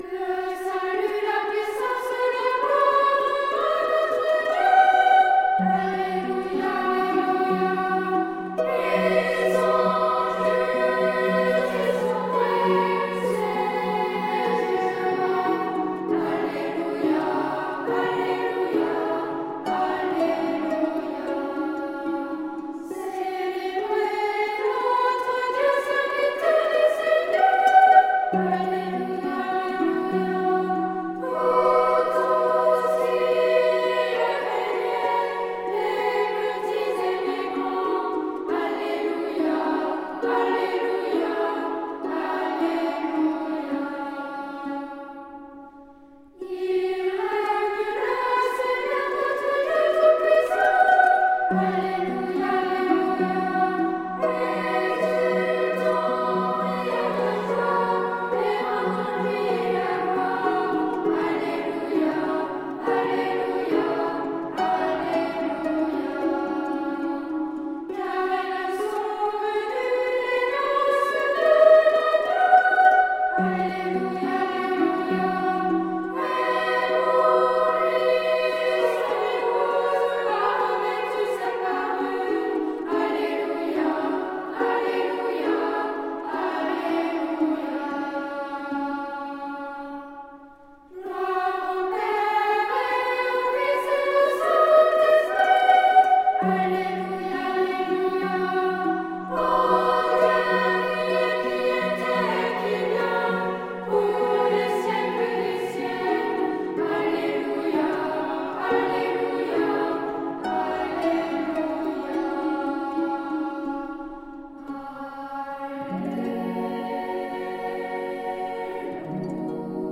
SSA (3 voix égale(s) d'enfants) ; Partition complète.
Sacré.
Instrumentation : Harpe (1 partie(s) instrumentale(s))
Tonalité : si majeur